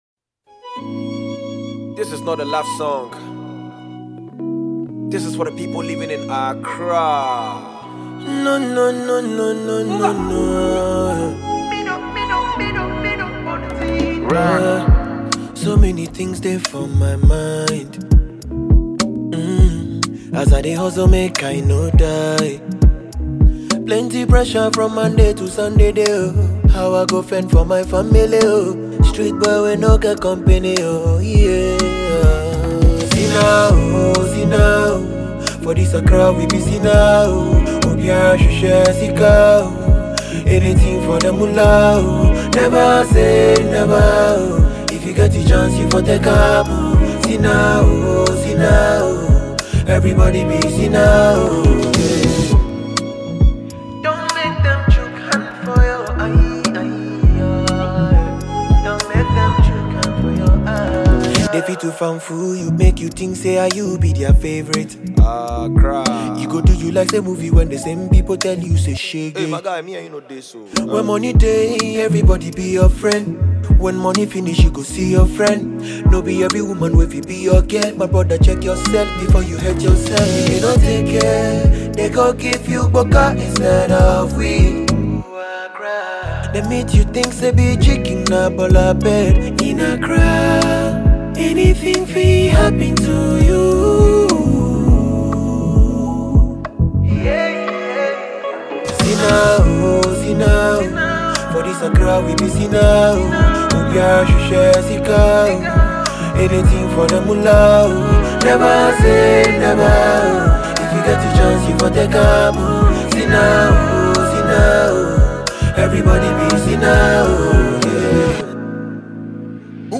Ghanaian musician